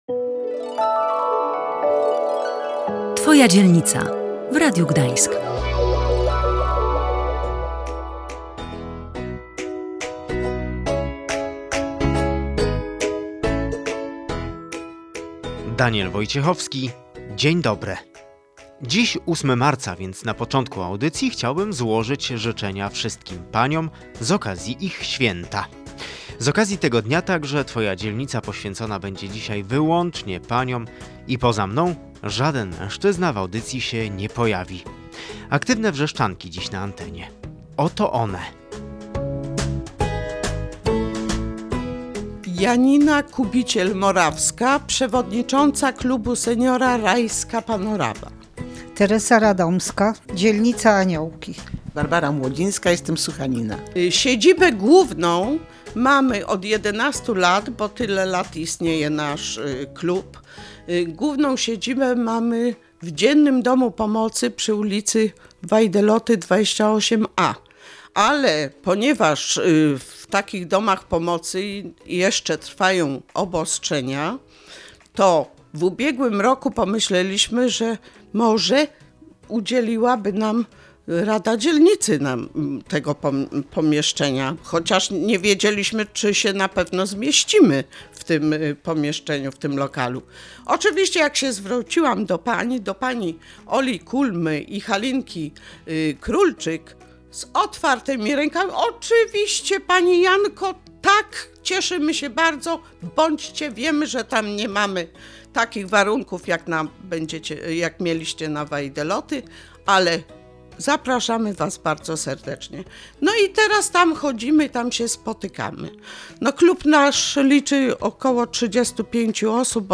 W audycji pojawią się seniorzy z lokalnego klubu Promyk, a dużo młodsze pokolenie reprezentuje Klub Młodego Wrzeszczaka.